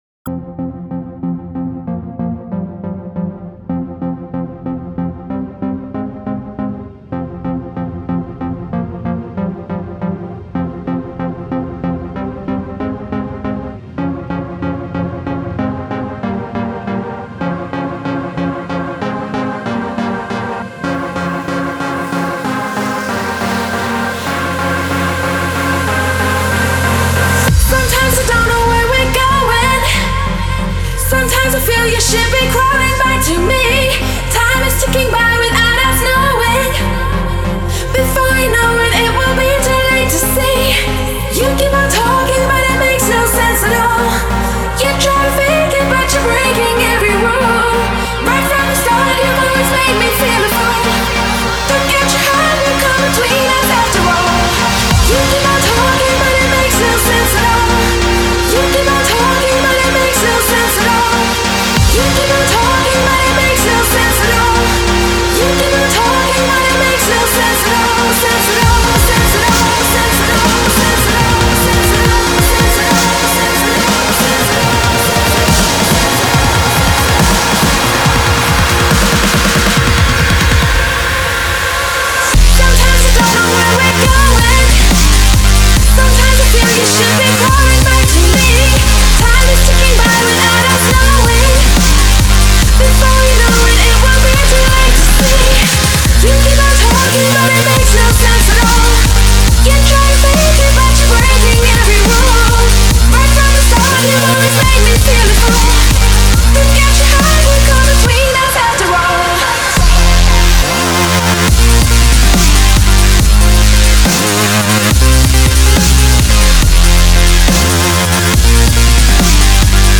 Стиль музыки: Dub Step